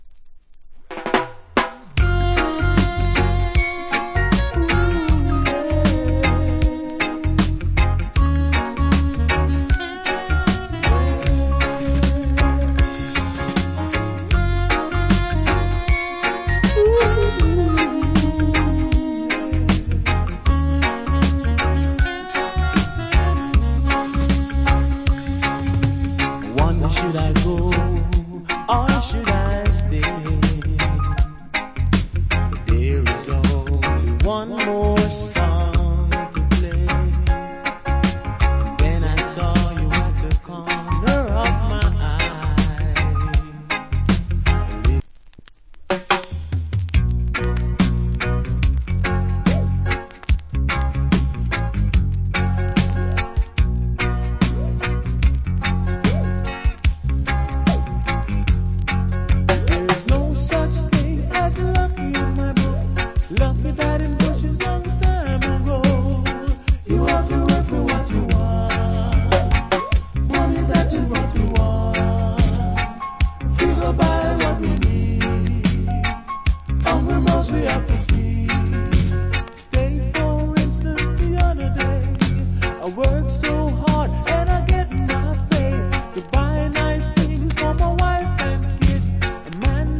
Cool Lovers Rock Vocal.